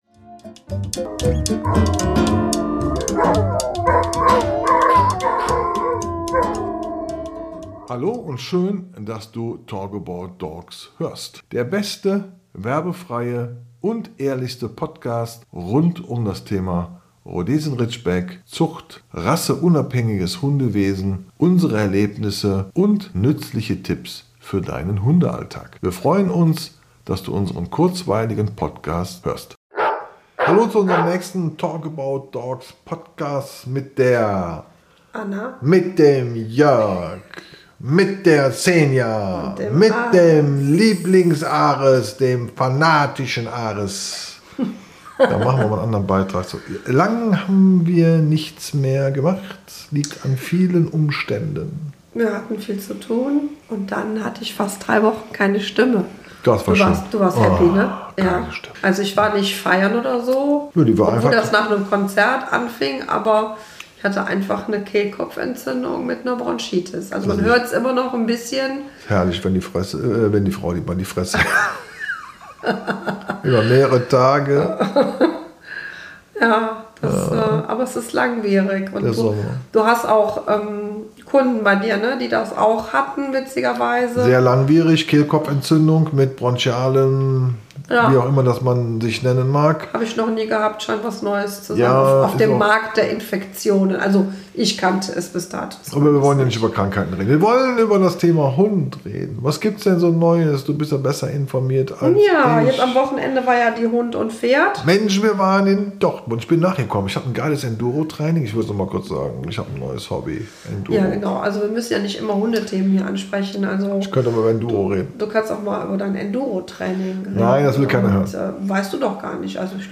Mit Gebell und Stimmen aus der Hundeszene gibt es besondere Einblicke zum Thema Alltagssituationen, Kennel und Zucht , Reisepodcast  sowie Welpenzauber .